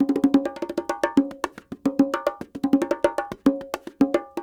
44 Bongo 16.wav